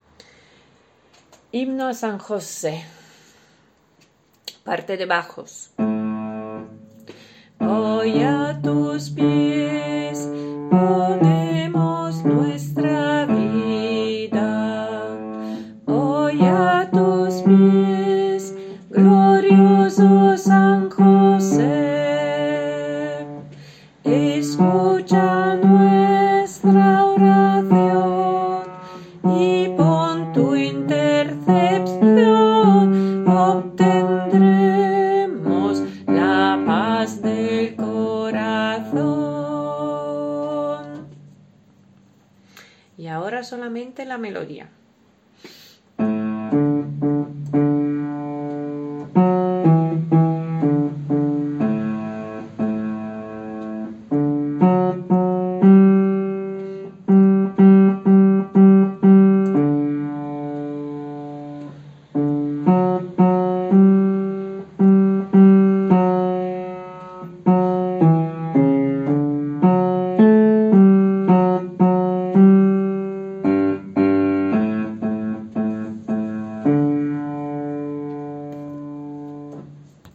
BAJO
h-sanjose-bajo.mp3